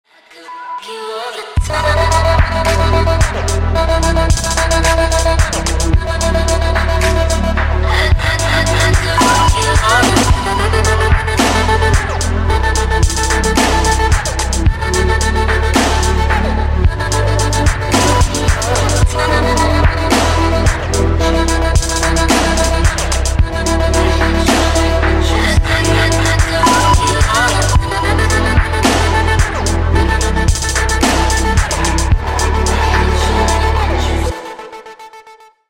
• Качество: 128, Stereo
club
Chill step